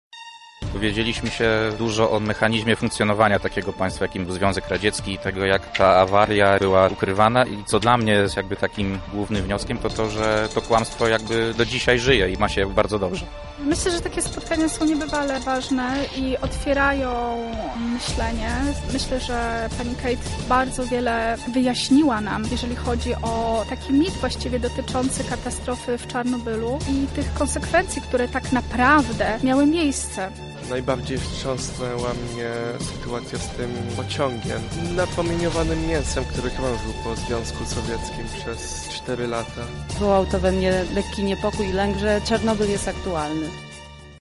Uczestnicy opowiedzieli naszej reporterce o swoich wrażeniach.